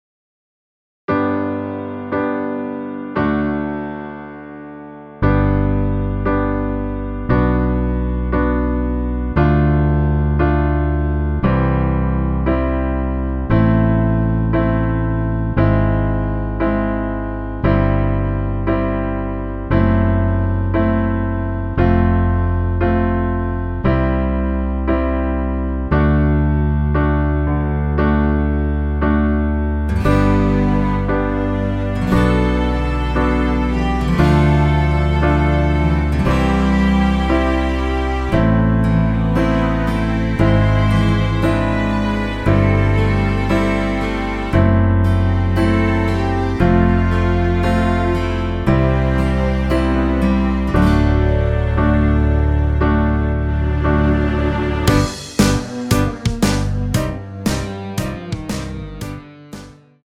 전주 없이 시작 하는 곡이라 노래 하시기 편하게 전주 2마디 만들어 놓았습니다.
◈ 곡명 옆 (-1)은 반음 내림, (+1)은 반음 올림 입니다.
앞부분30초, 뒷부분30초씩 편집해서 올려 드리고 있습니다.